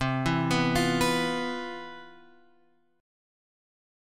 C Major 11th